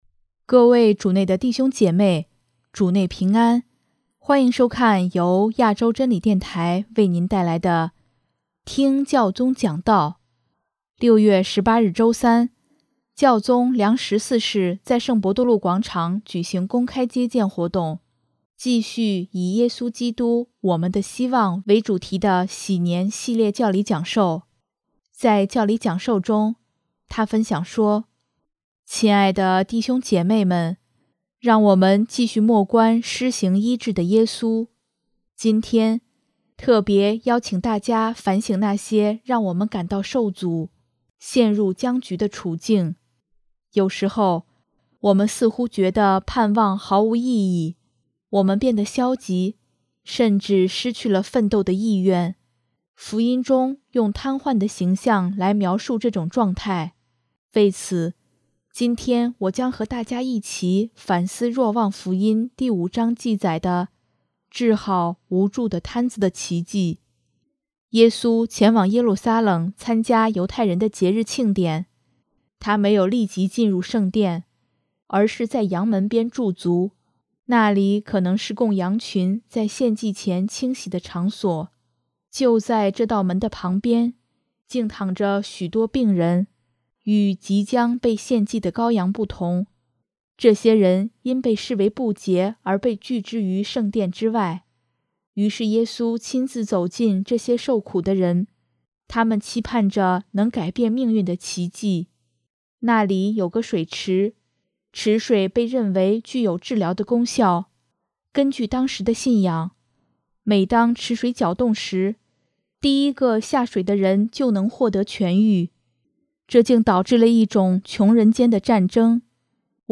6月18日周三，教宗良十四世在圣伯多禄广场举行公开接见活动，继续以“耶稣基督、我们的希望”为主题的禧年系列教理讲授。